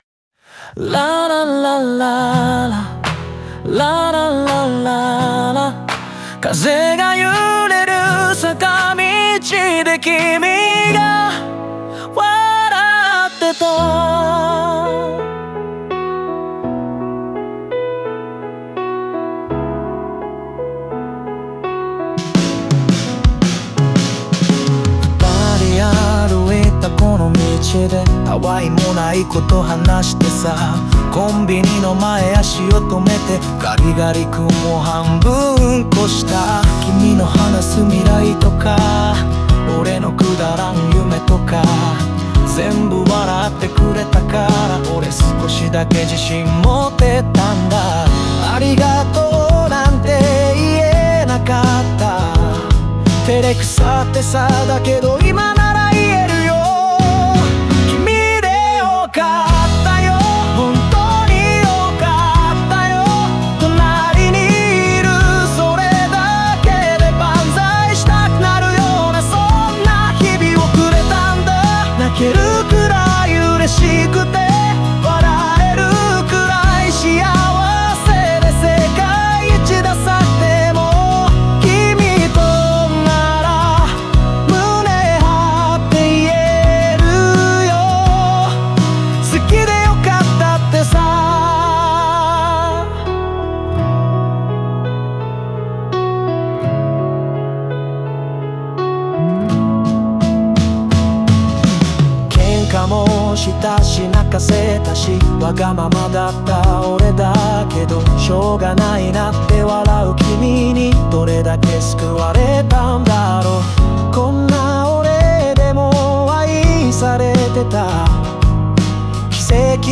オリジナル曲♪
ケンカや弱さも受け止め合いながら、かけがえのない存在となった恋人への思いを、明るくも切実なメロディにのせて歌い上げる。